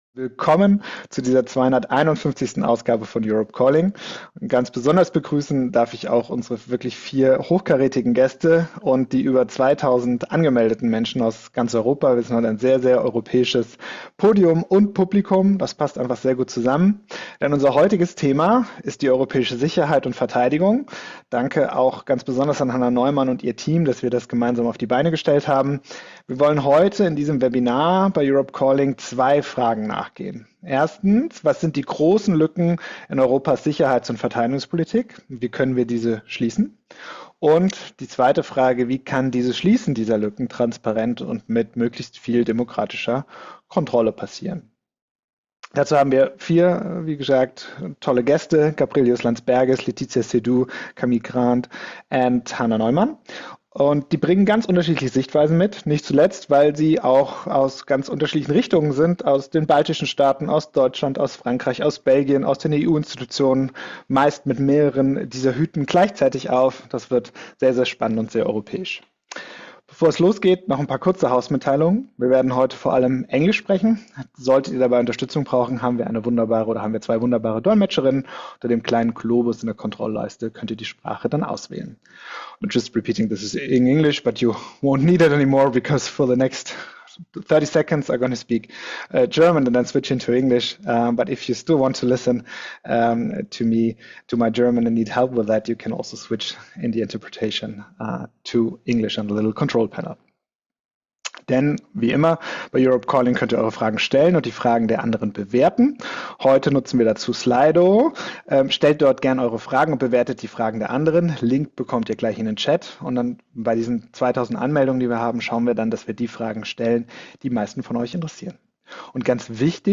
Aufzeichnung der 51. Ausgabe von Europe Calling. Am 28.01.2026 in Zusammenarbeit mit Hannah Neumann MdEP.